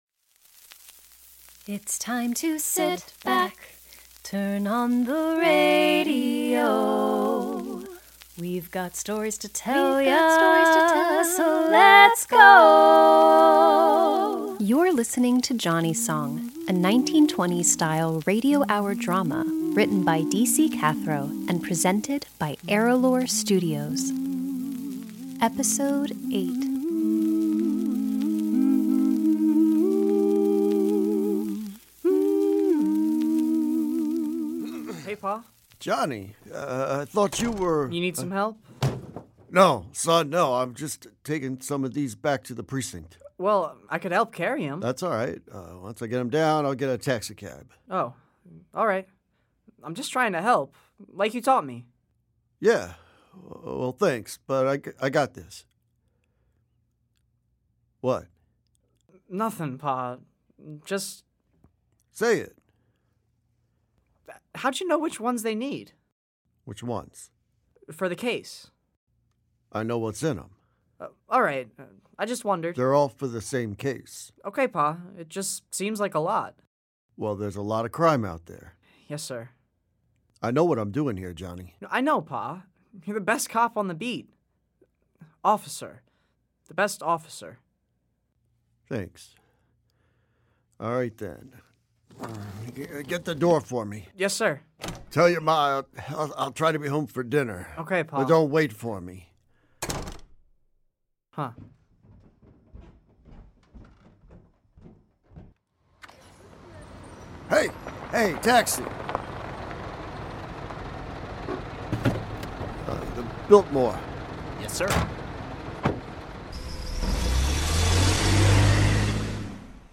Johnnie's Song: A 1920s Style Radio Hour Drama Podcast - Episode 8: Undercover | Free Listening on Podbean App